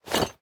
equip_iron5.ogg